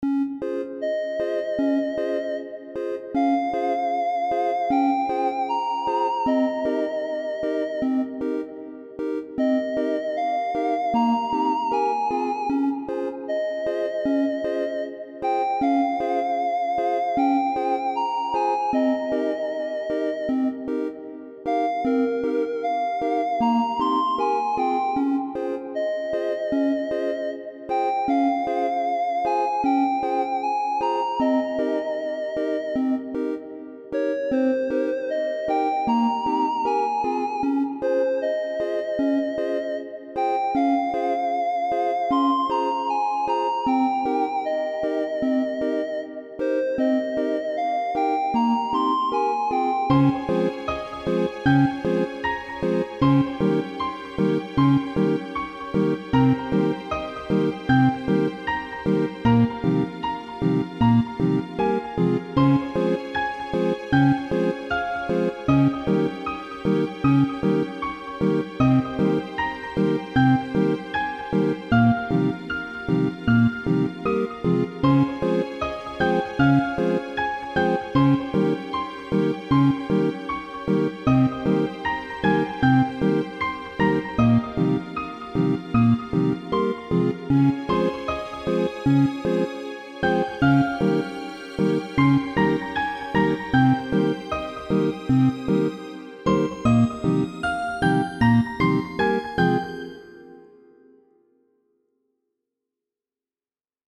well I was playing with Undertale soundfonts and made this